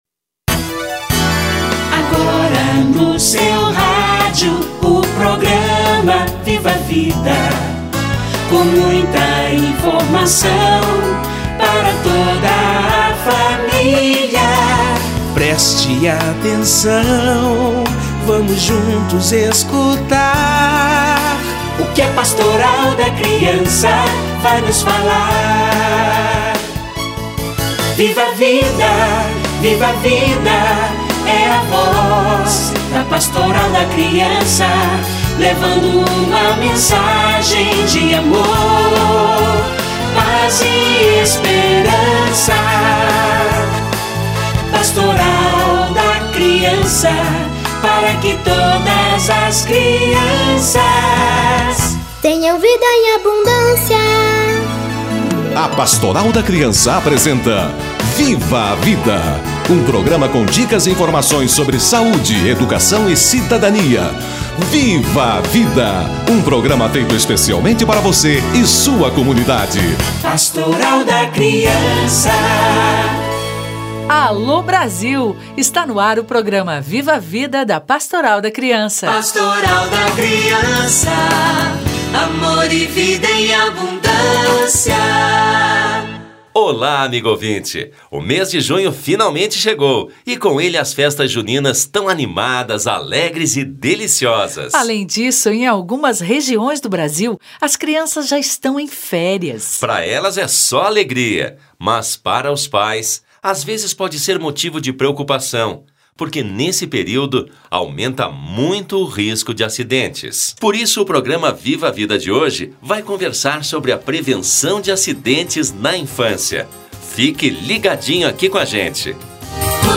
Prevenção de acidentes com as crianças - Entrevista